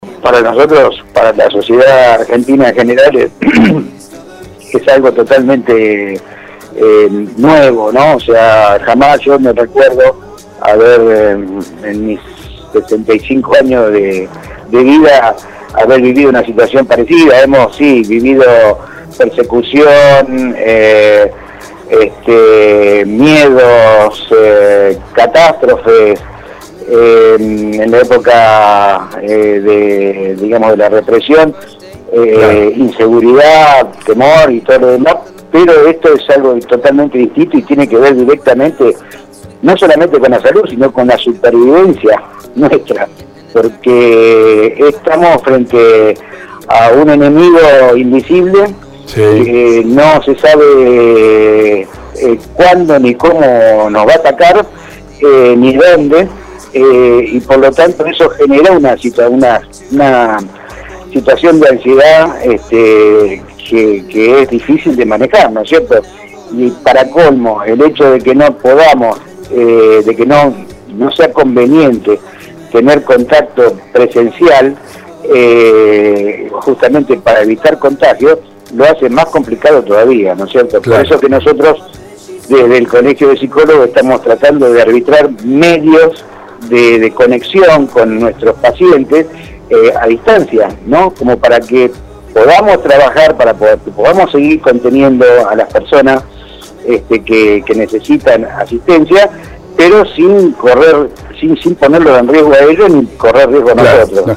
Interesante entrevista de este martes